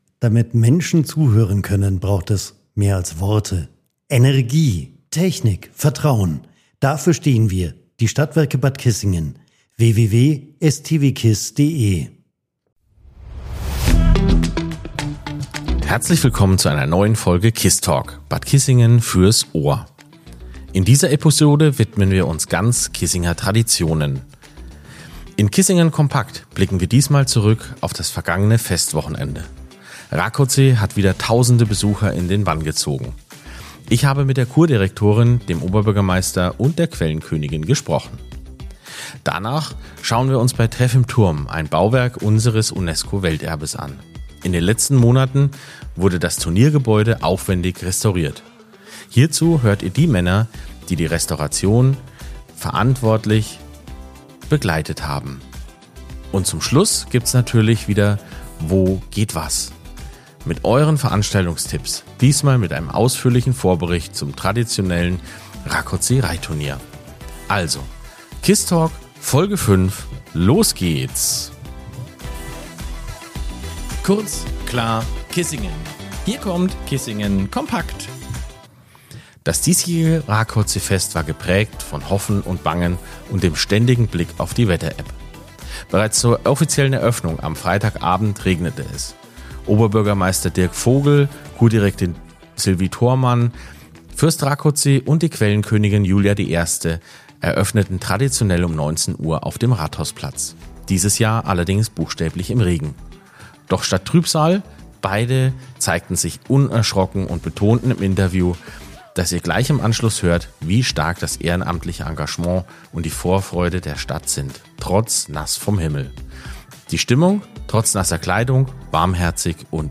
charmante Stimmen und persönliche Eindrücke inklusive. Im „Treff im Turm“ widmen wir uns dem Turniergebäude, einem echten Kissinger Kulturbau.